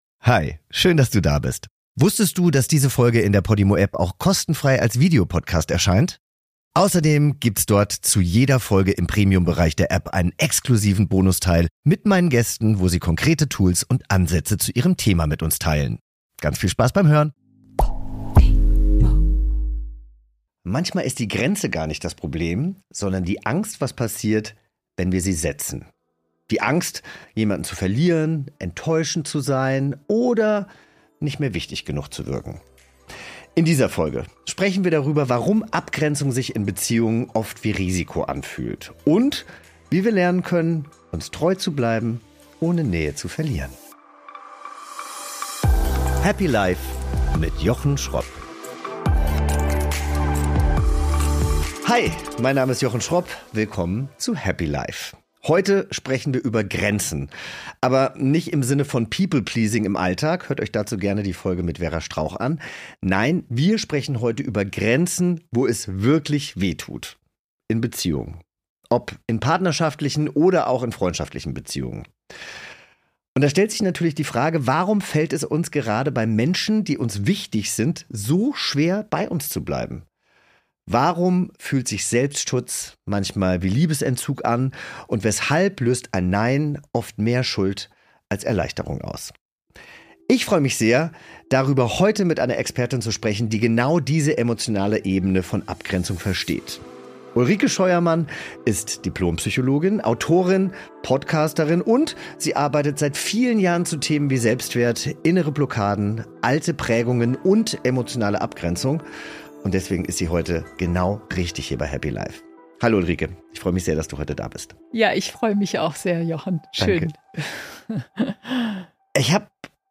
Host: Jochen Schropp